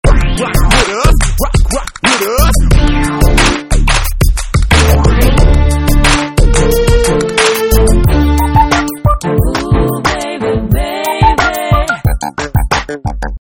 Rap - RnB
Hiphop